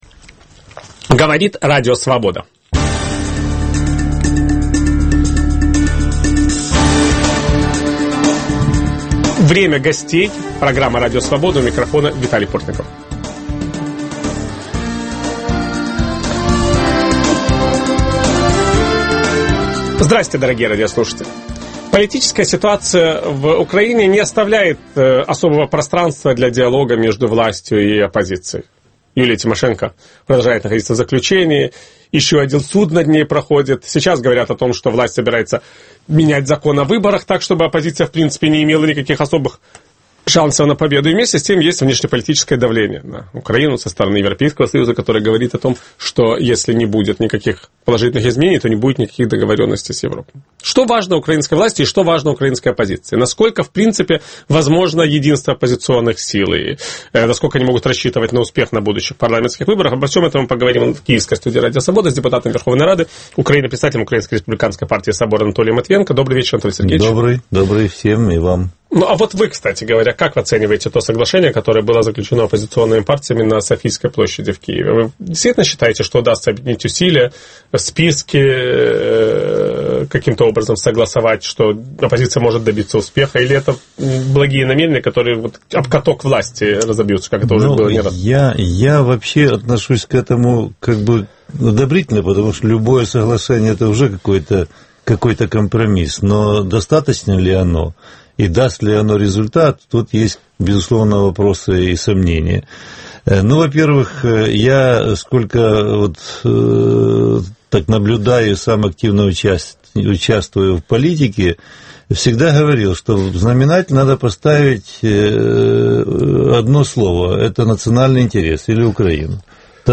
Вернется ли ощущение реальности к украинской власти? Участвует депутат Верховной рады Украины Анатолий Матвиенко.